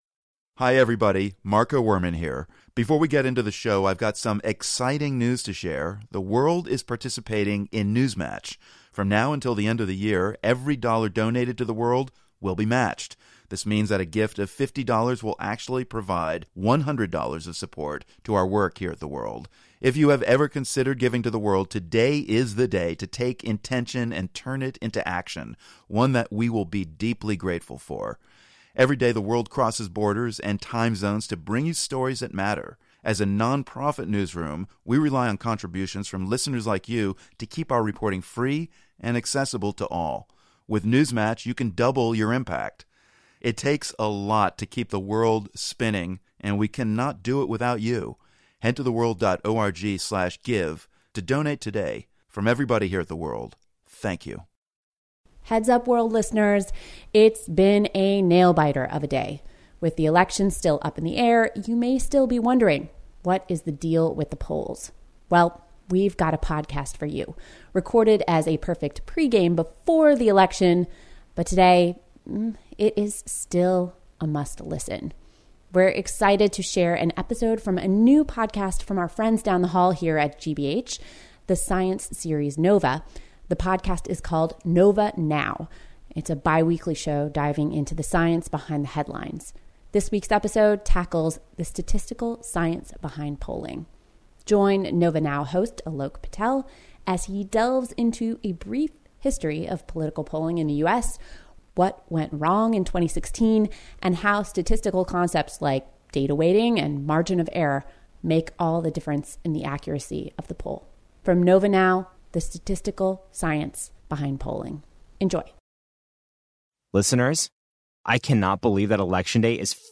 interviews a pollster and a statistician